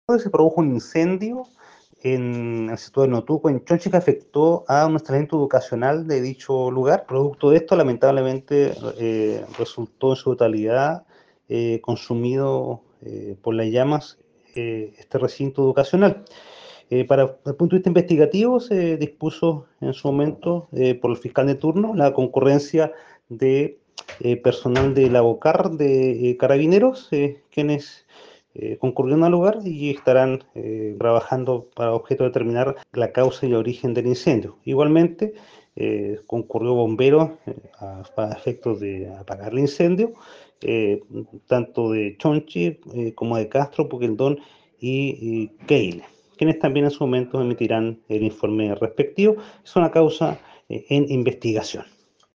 Desde la fiscalía de Castro se entregó también una declaración del fiscal Javier Calisto por la indagatoria que se lleva adelante con ocasión del incendio que arrasó con la escuela.